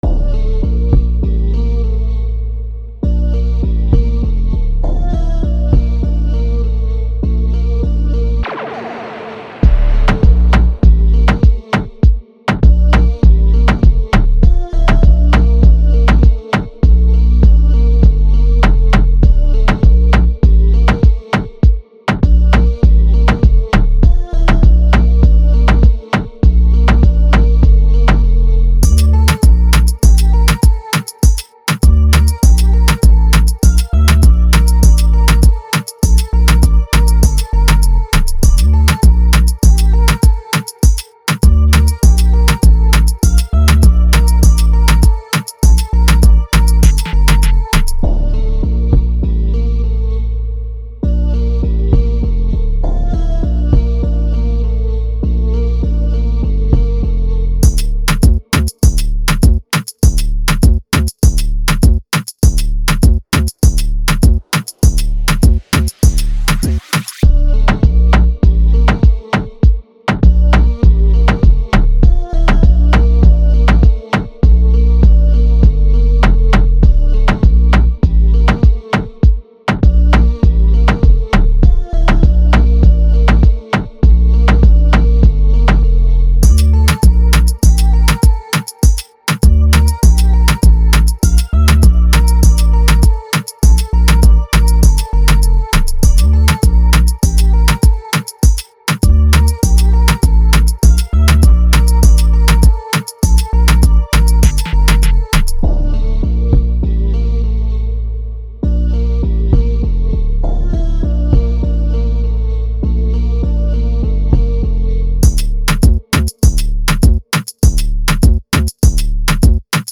Afrotrap
dMinor